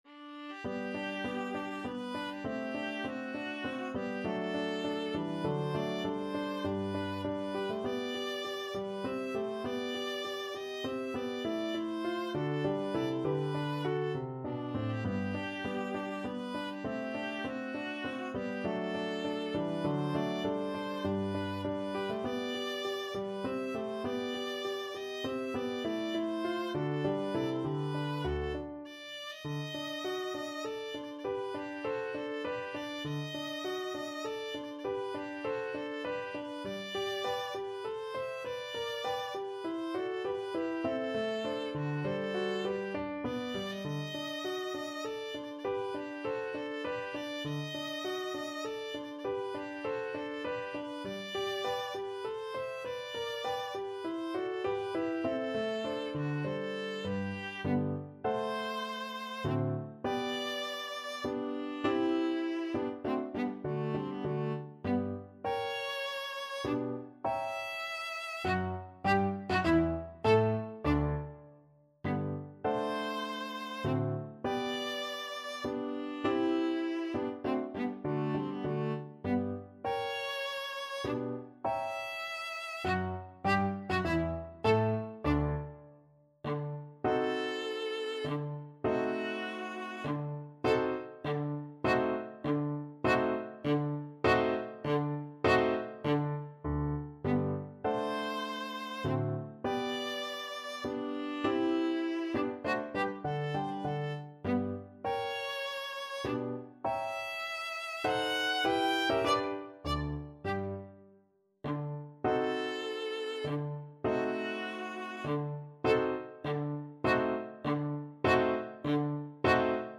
Menuetto Moderato e grazioso
3/4 (View more 3/4 Music)
D4-G6
Classical (View more Classical Viola Music)